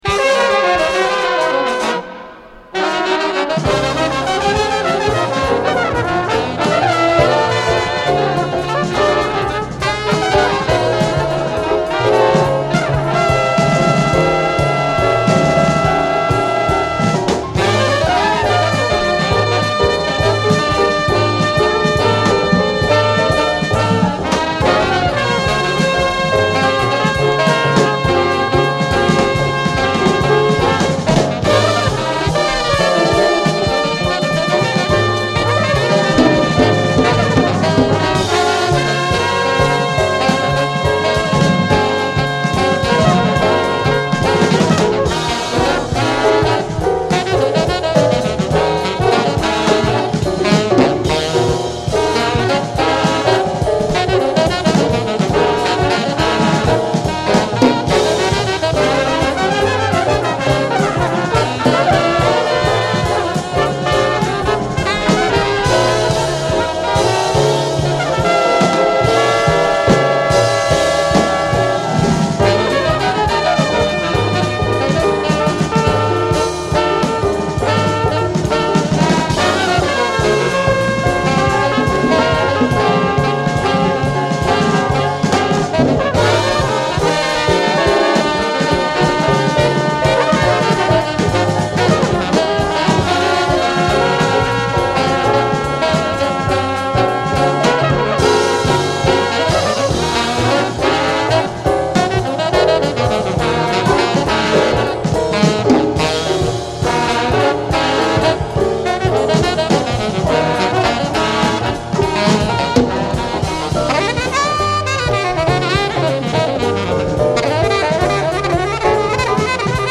Avant-gardist